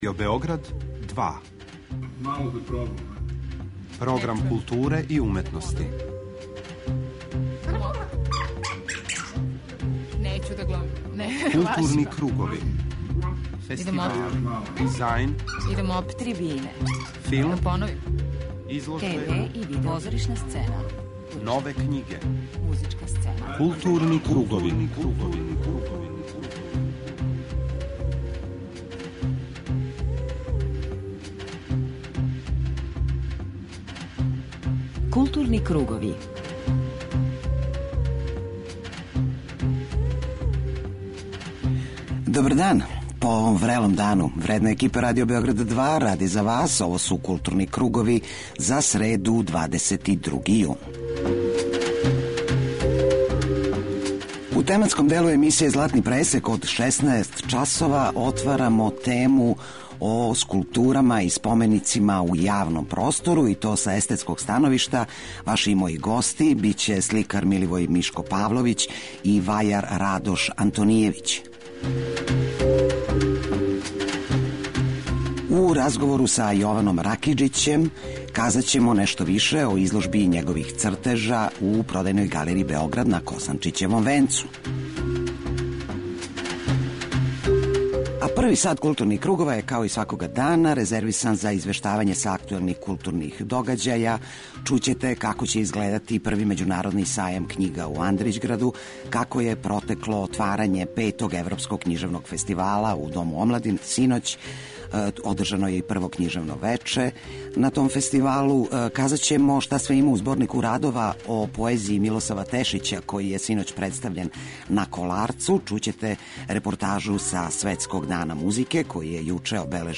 У првом сату емисије, чућете извештаје са отварања петог Београдског фестивала европске књижевности у Дому омладине, са промоције зборника радова о поезији Милосава Тешића на Коларцу и са конференције за новинаре на којој је најављен први међународни Сајам књига у Андрићграду.